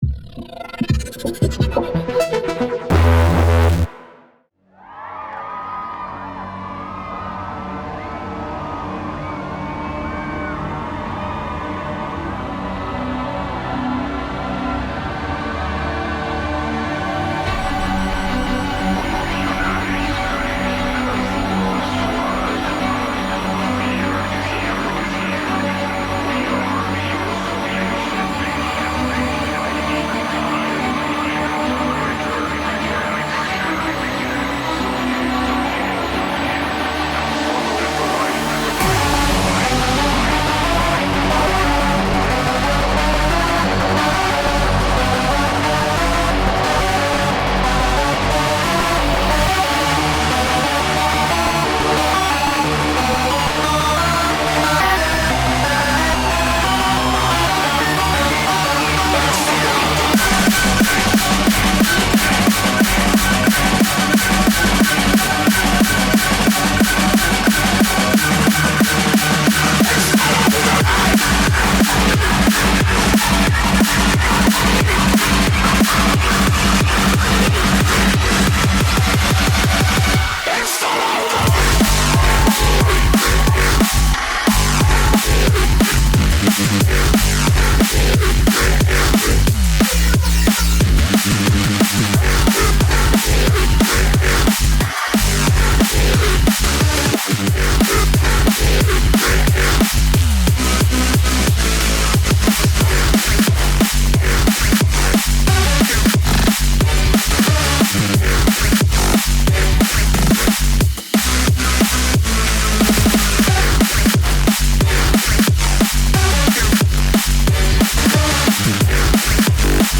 Drum and Bass | Live | Set | Mix